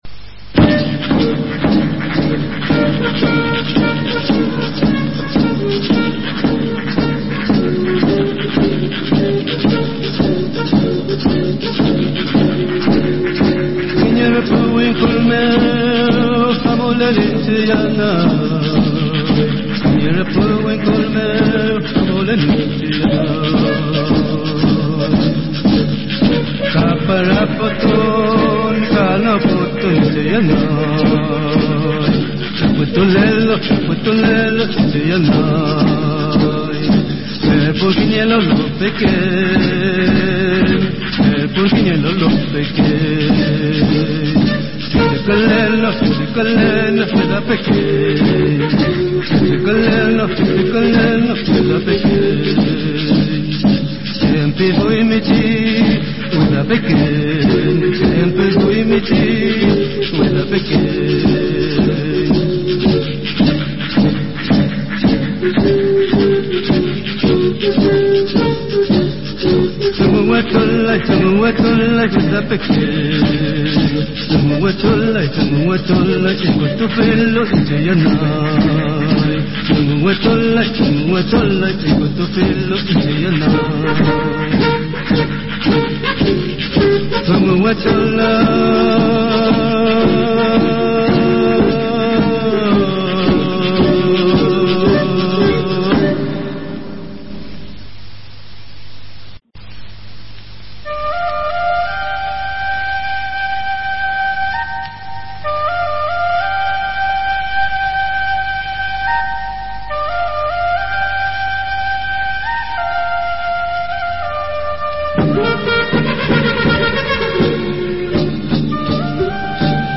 📻 Te invitamos a escuchar el programa radial We Newenche.